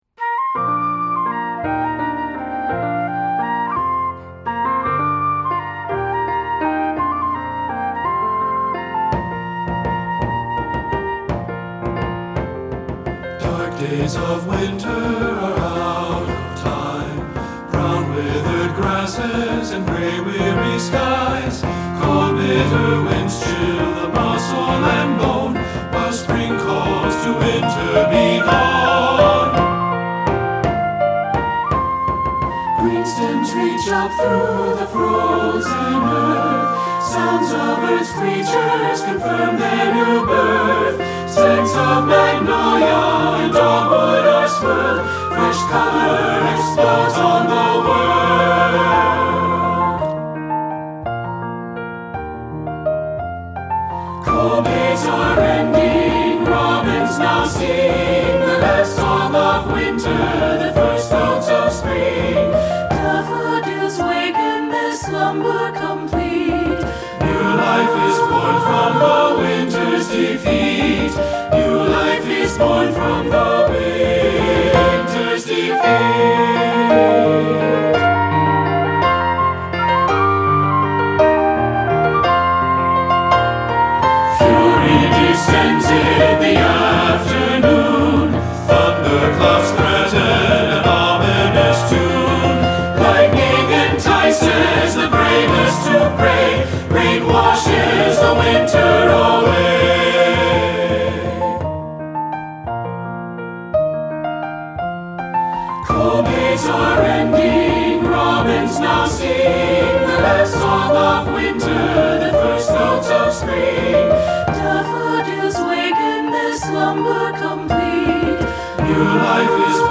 Choral Concert/General
SAB